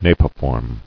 [na·pi·form]